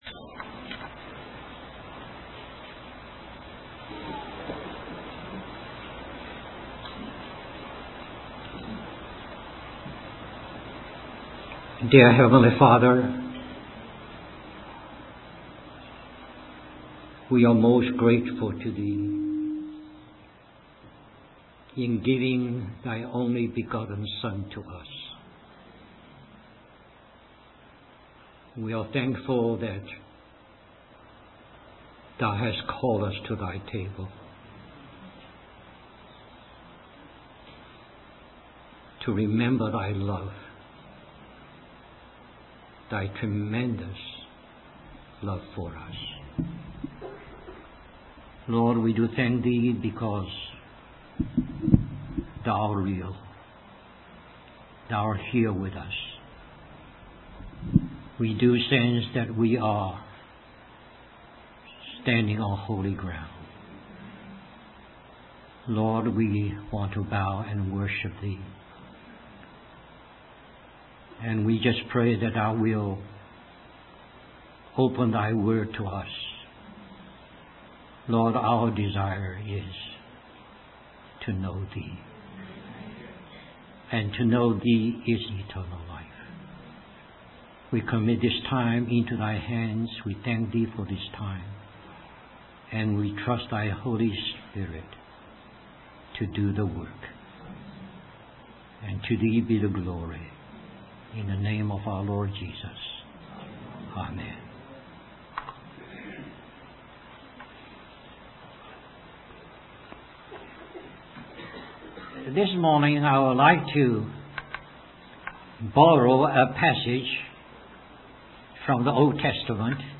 In this sermon, the preacher emphasizes the importance of spiritual reality and the need for believers to actively cooperate with the Holy Spirit. He explains that spiritual reality is not necessarily something spectacular, but rather a daily process of being led by the Spirit of Truth.